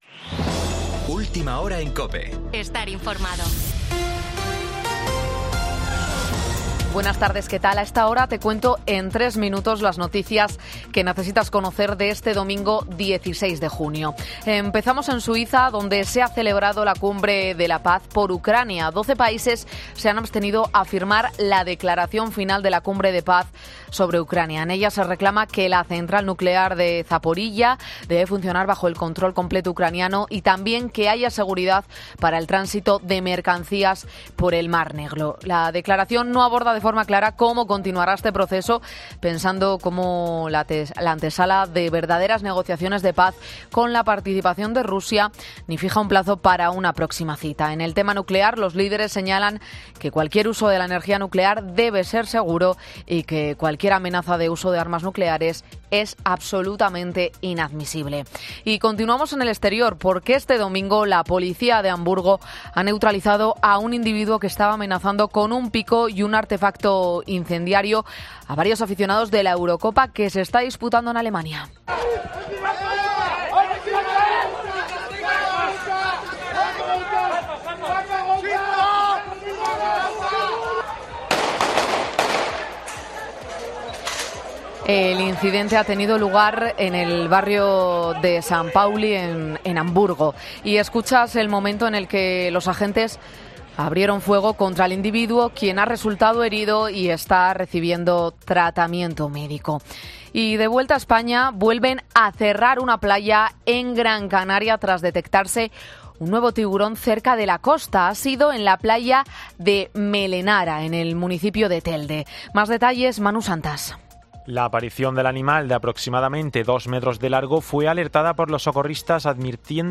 Boletín 20.30 horas del 16 de junio de 2024